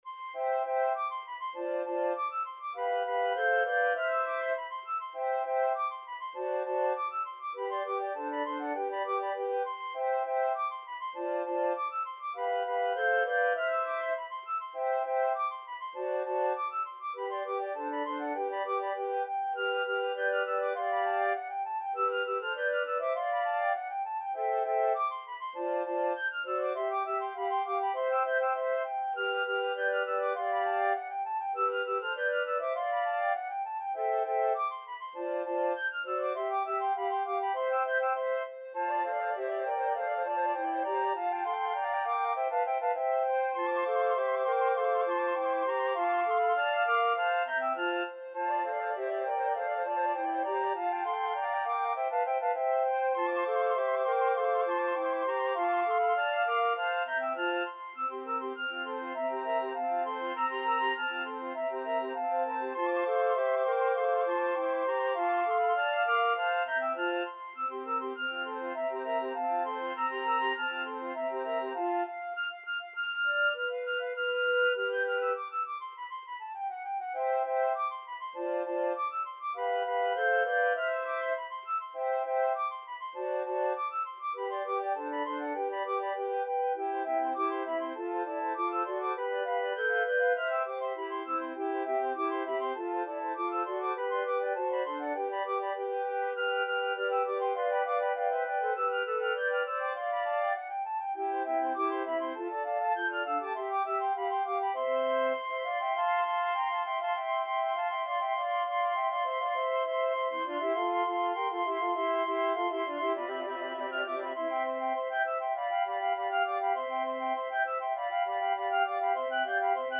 Flute 1Flute 2Flute 3Flute 4
2/4 (View more 2/4 Music)
Classical (View more Classical Flute Quartet Music)